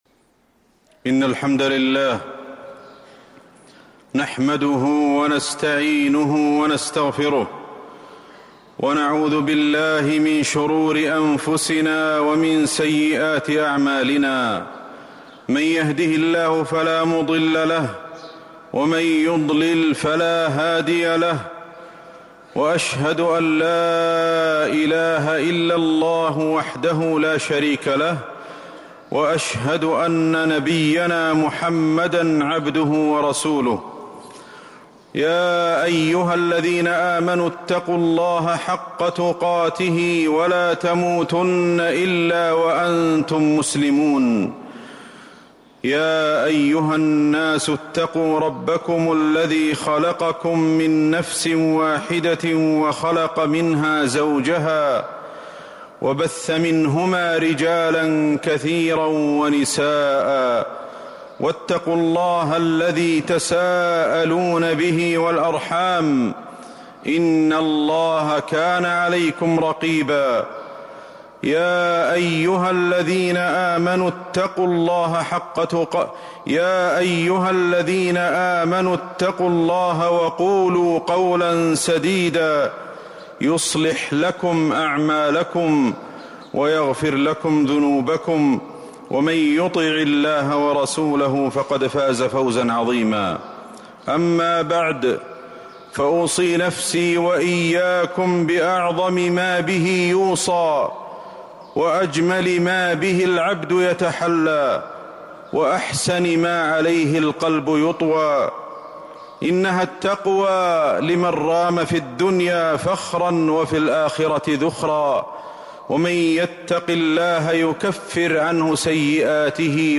جودة عالية